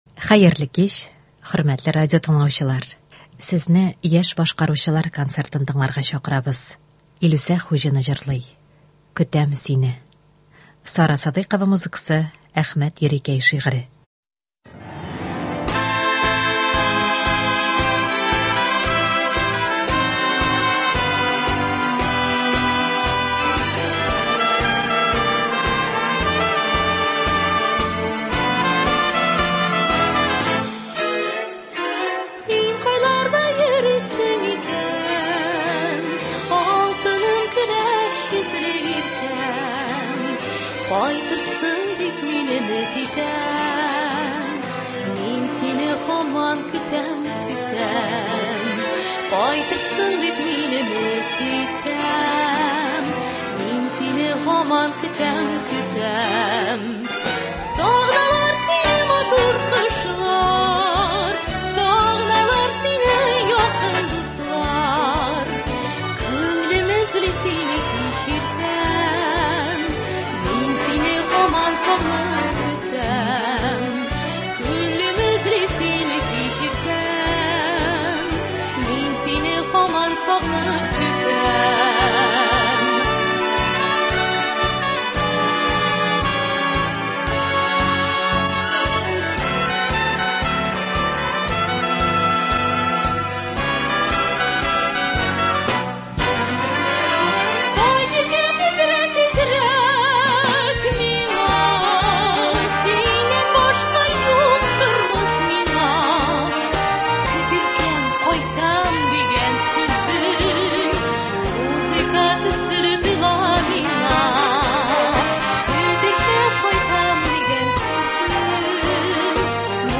Яшь башкаручылар концерты.
Концерт (28.09.22)